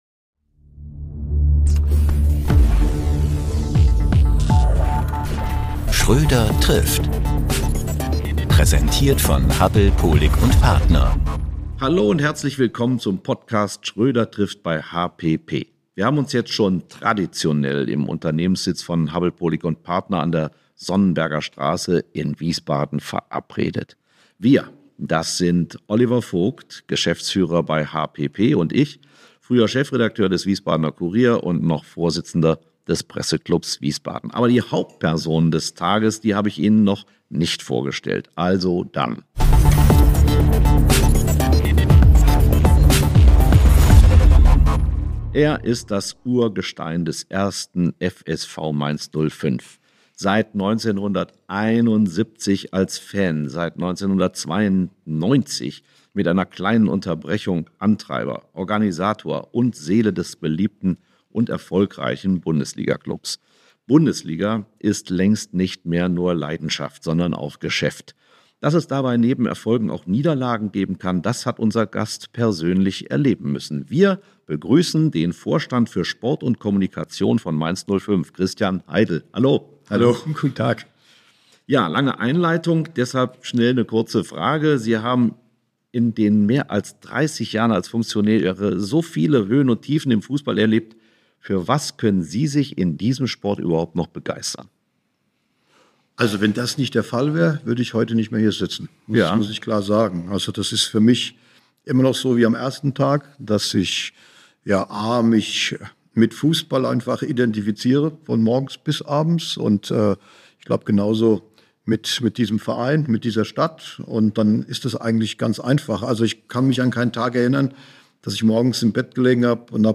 inspirierenden Gespräch